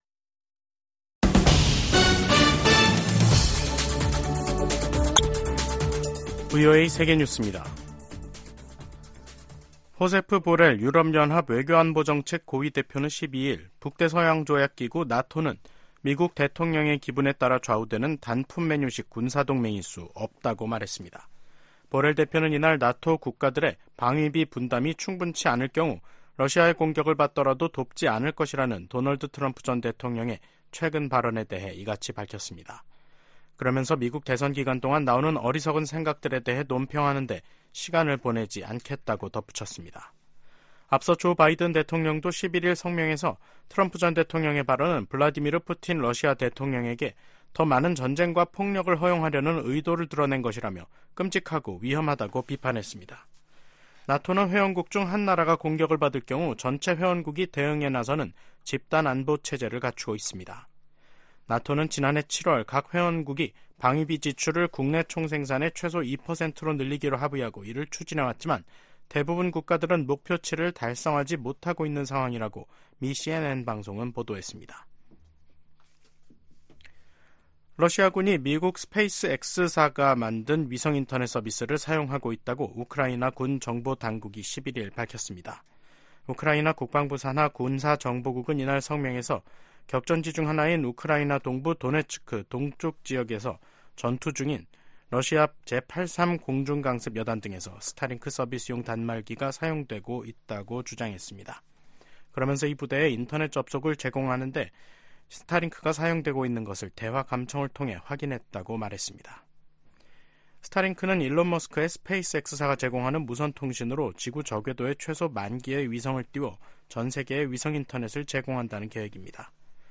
VOA 한국어 간판 뉴스 프로그램 '뉴스 투데이', 2024년 2월 12일 3부 방송입니다. 북한 국방과학원이 조종 방사포탄과 탄도 조종체계를 새로 개발하는 데 성공했다고 조선중앙통신이 보도했습니다. 지난해 조 바이든 행정부는 총 11차례, 출범 이후 연간 가장 많은 독자 대북제재를 단행한 것으로 나타났습니다. 미국과 한국·일본의 북한 미사일 경보 정보 공유는 전례 없는 3국 안보 협력의 상징이라고 미 국방부가 강조했습니다.